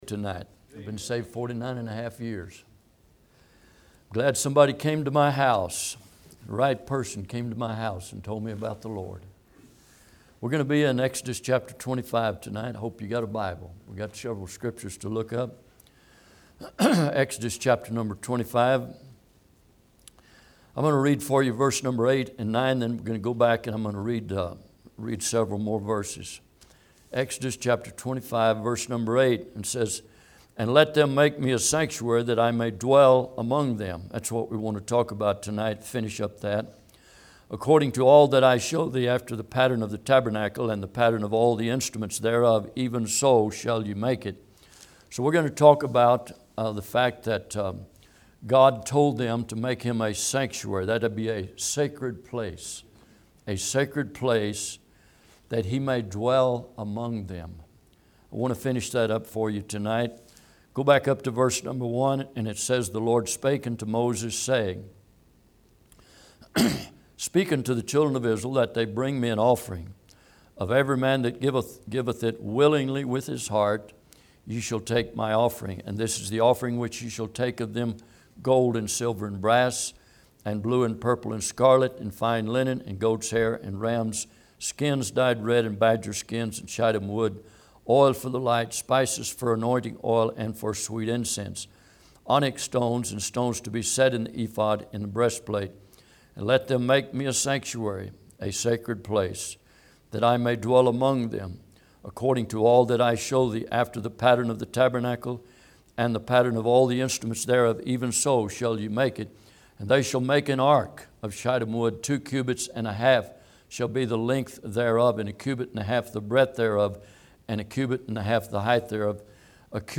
Service Type: Midweek